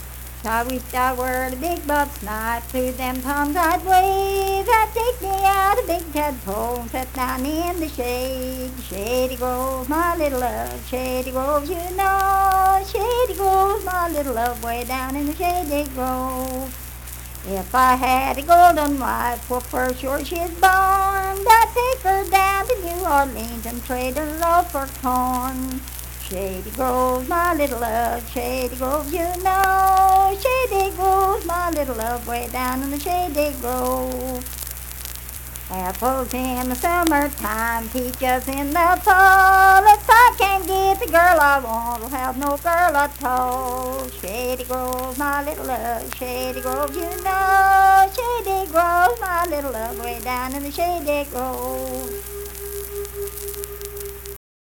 Unaccompanied vocal music performance
Verse-refrain 3(4) & R(4).
Minstrel, Blackface, and African-American Songs
Voice (sung)
Logan County (W. Va.)